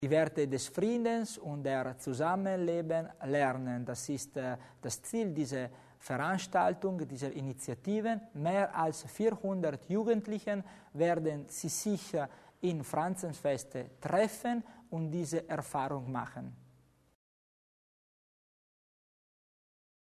Landesrat Tommasini zum Jugendfest in Franzensfeste